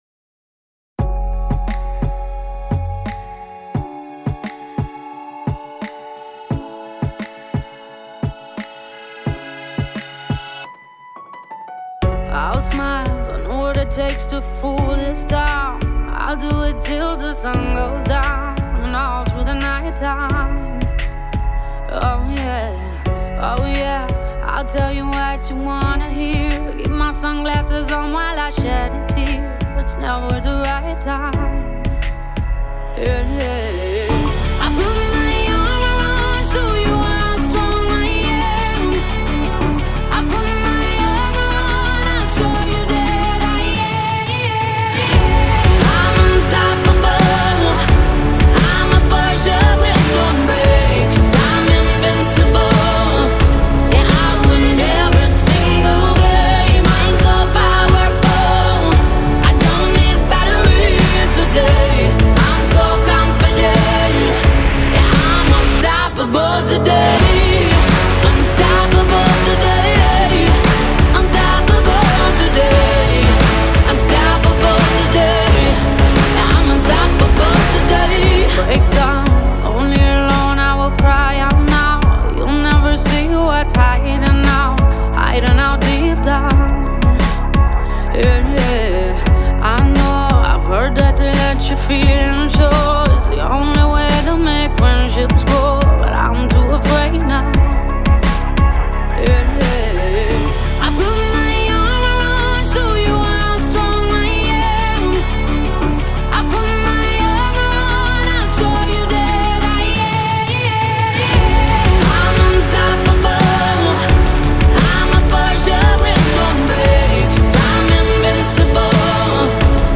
raw vulnerability masked by towering anthemic power.
With its stomping beats, soaring vocals
one voice, raw and resonant